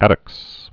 (ătəks), Crispus 1723?-1770.